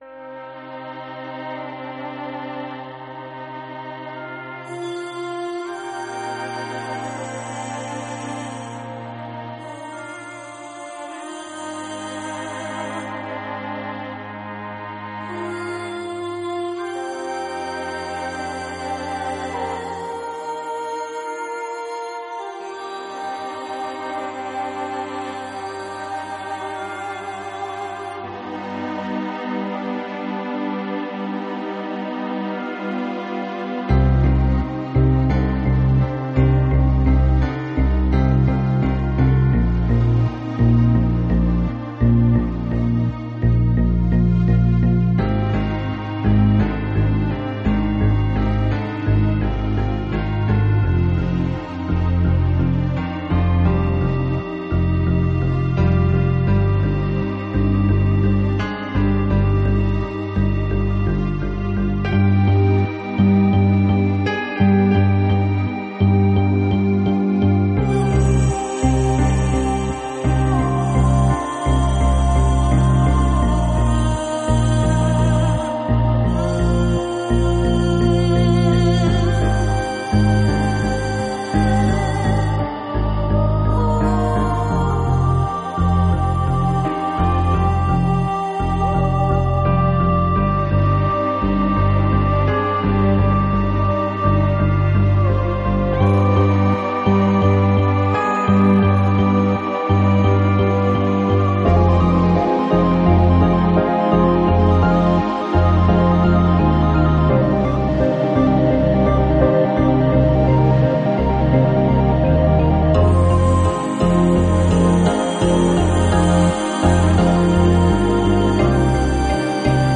инструментальным номером